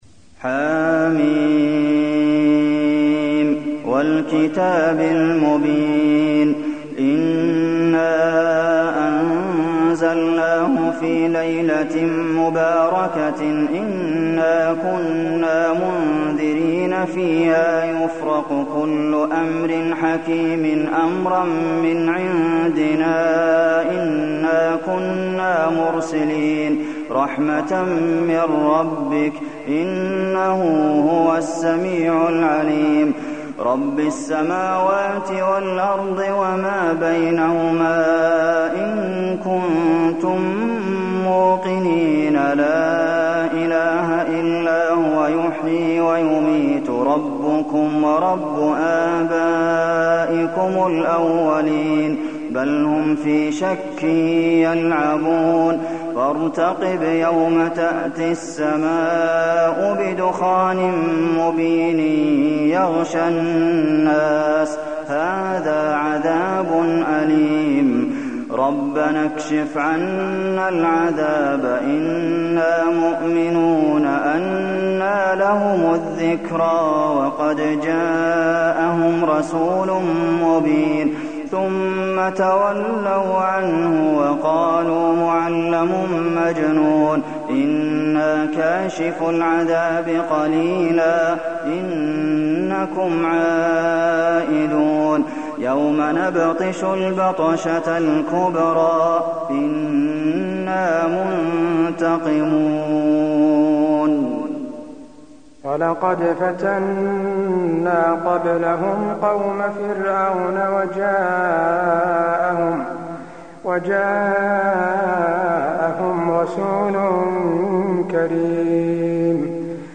المكان: المسجد النبوي الدخان